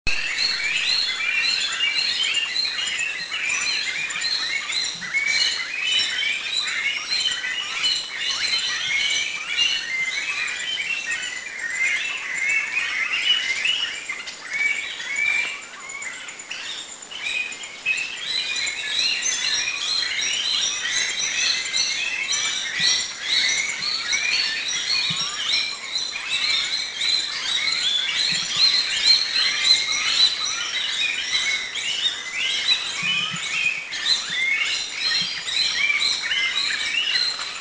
Rufous Coucal 1
calls
Centropus unirufus
RufousCoucal1.mp3